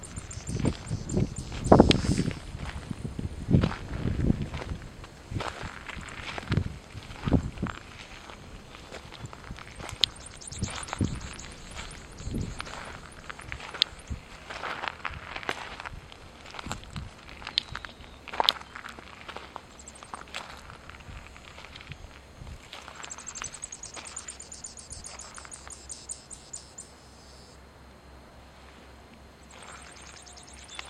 Grassland Yellow Finch (Sicalis luteola)
Province / Department: Tucumán
Location or protected area: Trancas
Condition: Wild
Certainty: Photographed, Recorded vocal